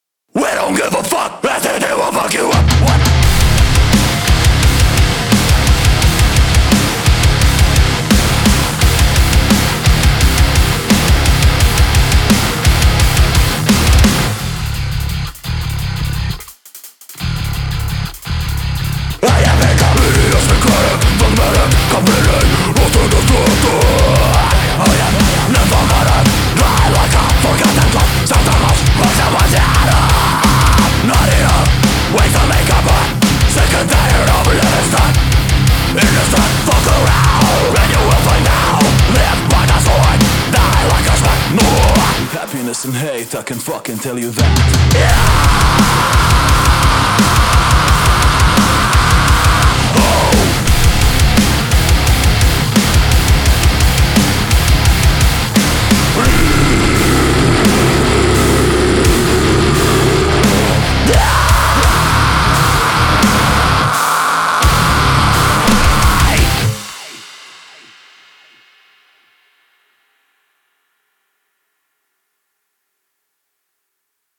Anger galore. Frustration unbound.